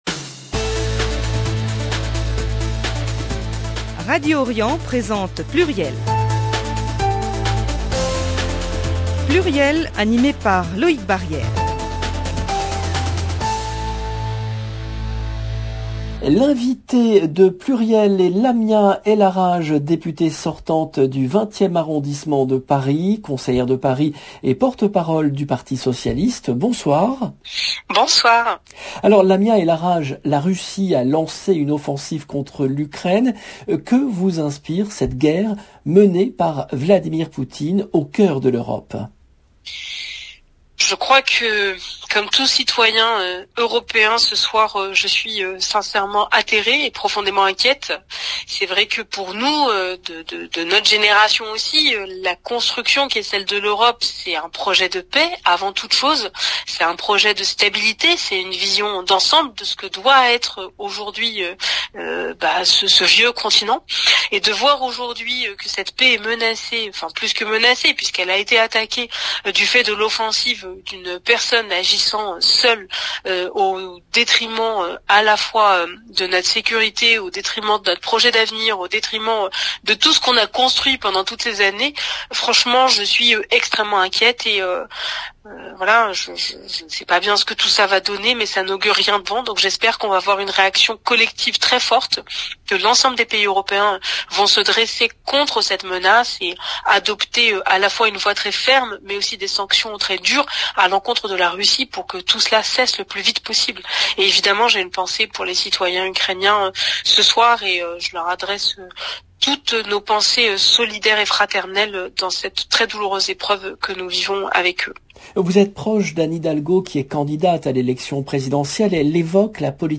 L’invitée de Pluriel est Lamia El Aaraje , députée sortante du 20e arrondissement, conseillère de Paris et porte-parole du Parti Socialiste